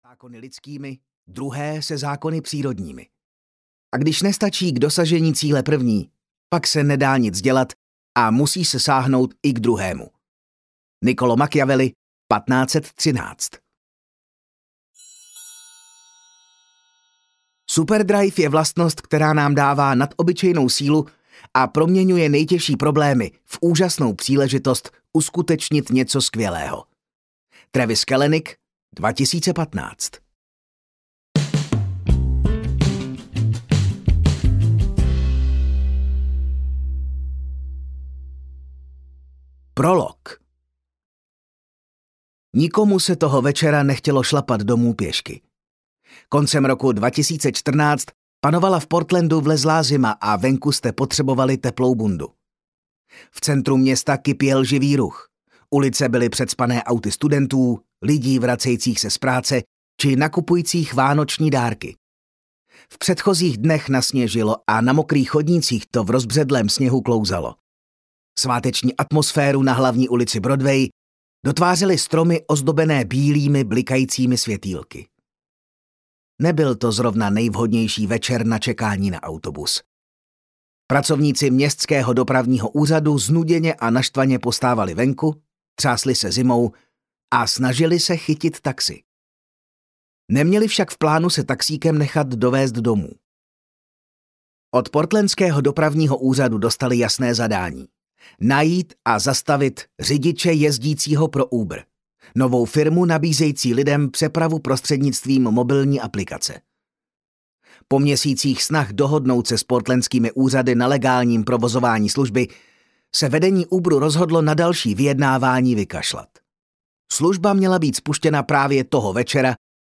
Válka o Uber audiokniha
Ukázka z knihy